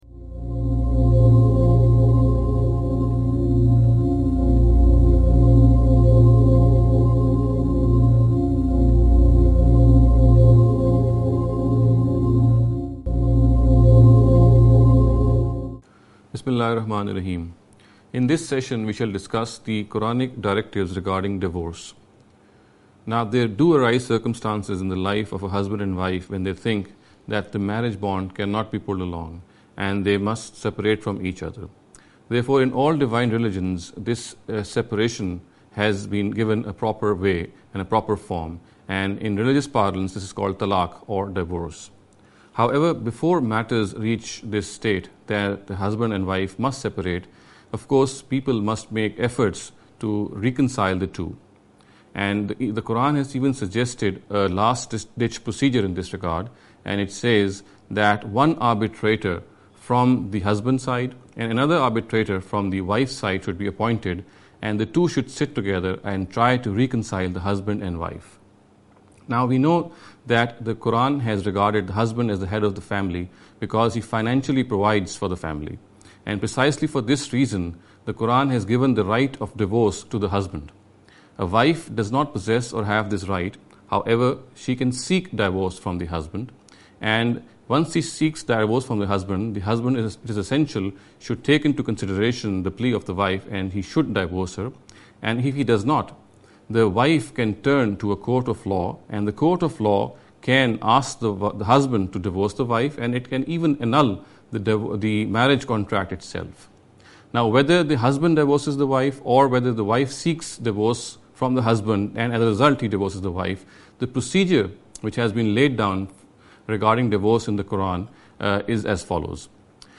This lecture series will deal with some misconception regarding the Understanding The Qur’an.